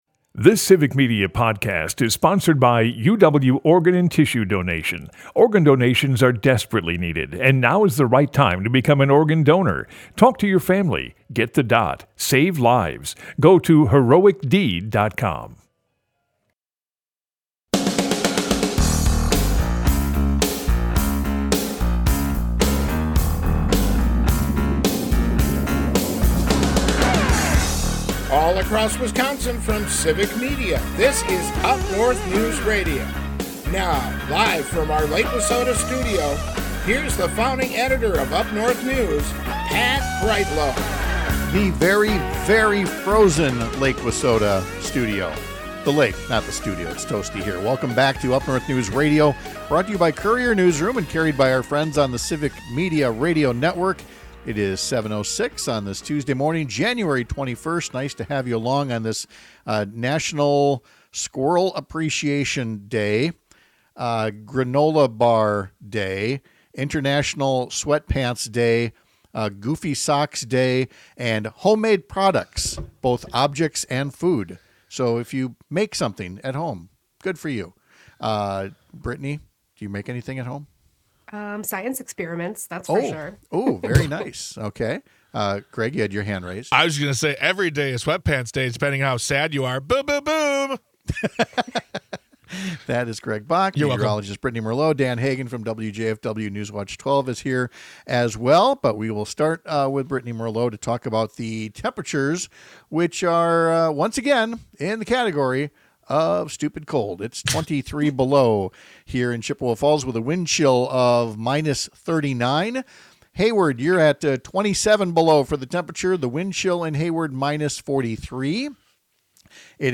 Broadcasts live 6 - 8 a.m. across the state!
Congressman Mark Pocan talks to us about spending the day working with groups likely to be hurt by Trump’s hate-based actions.